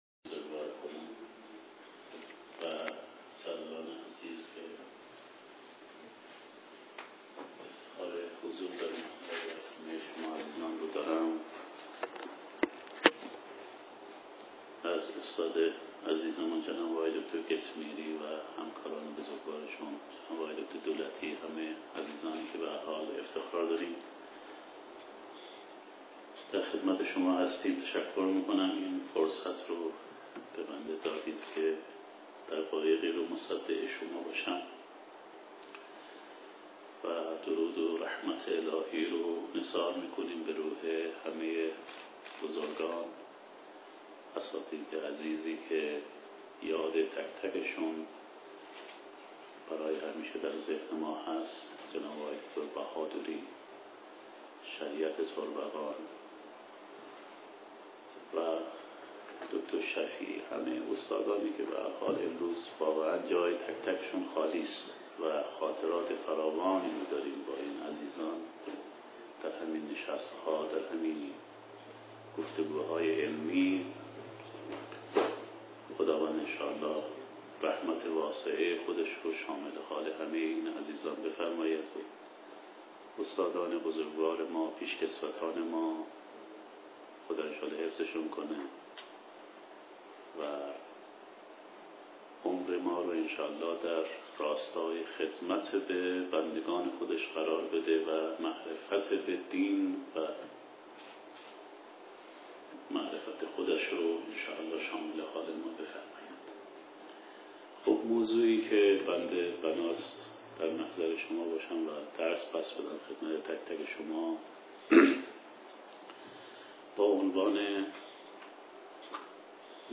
سمینارها و همایشها